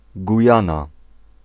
Ääntäminen
Vaihtoehtoiset kirjoitusmuodot Guiana Gayana Ääntäminen US US UK : IPA : /ɡaɪˈɑː.nə/ IPA : /ɡaɪˈæn.ə/ US : IPA : /ɡaɪˈɑː.nə/ IPA : /ɡaɪˈæn.ə/ Lyhenteet (laki) Guy.